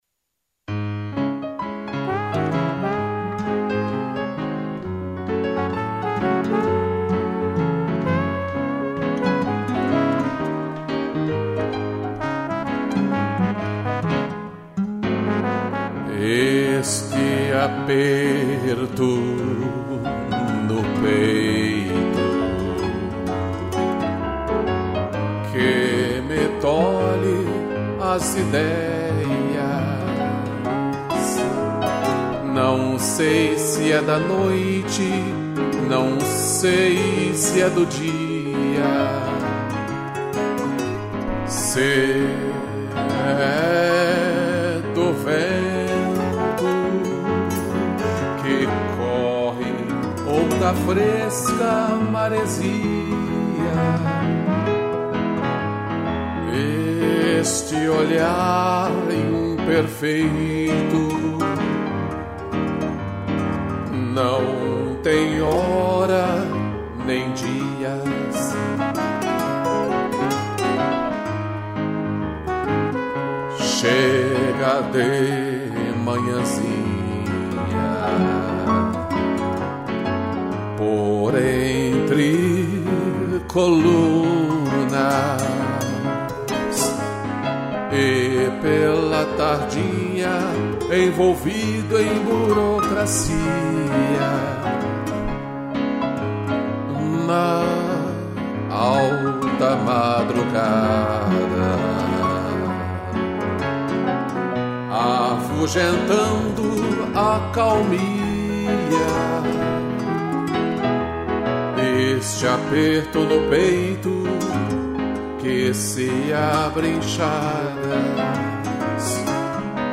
voz e violão
2 pianos e trombone